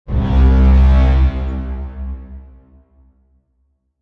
Sound Effects
Dirty Siren